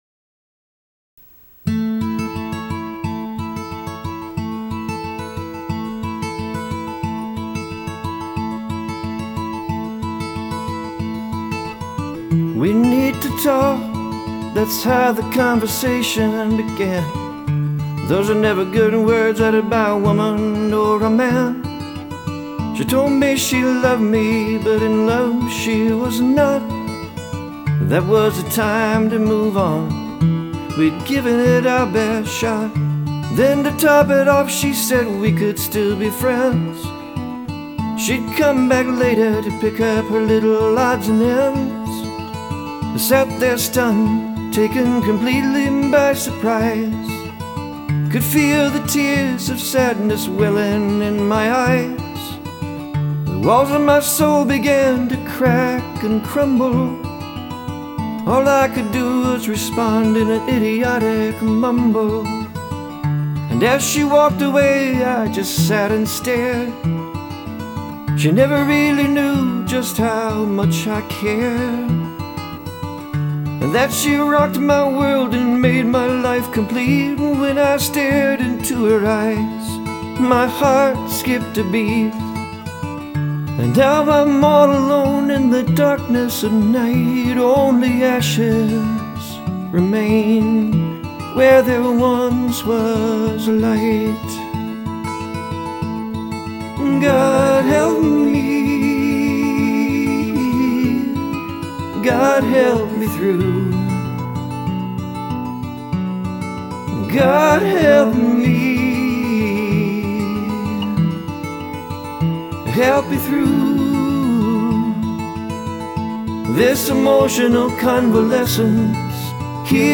• Soft acoustic guitar with soft male vocals again.
• Just love the gentle guitar picking style of this artist.